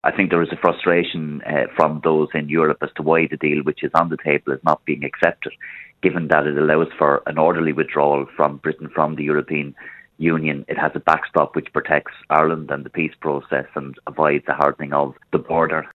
Sinn Fein’s Brexit spokesperson is David Cullinane: